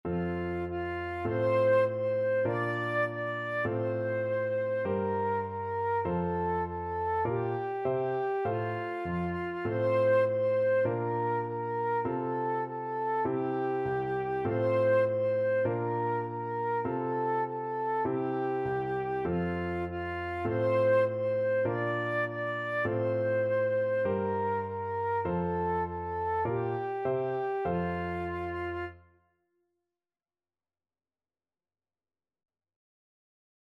Flute
4/4 (View more 4/4 Music)
Moderato
C major (Sounding Pitch) (View more C major Music for Flute )
Traditional (View more Traditional Flute Music)